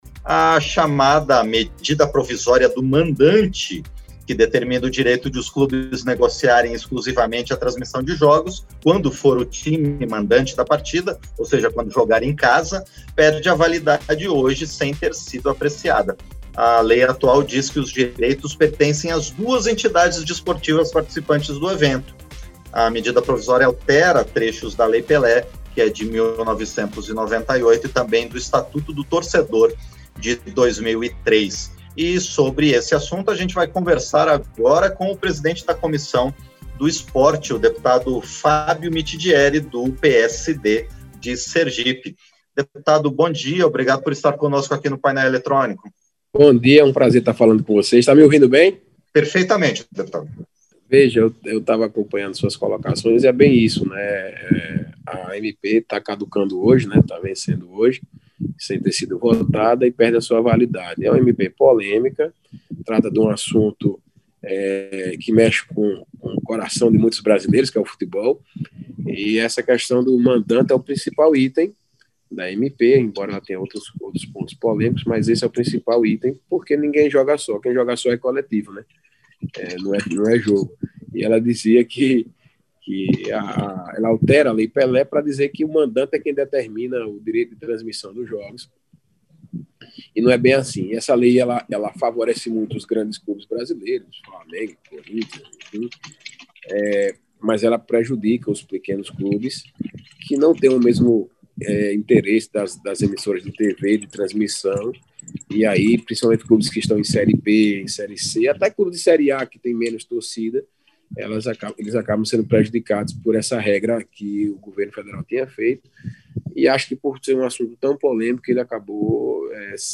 Entrevista - Dep. Fábio Mitidieri (PSD-SE)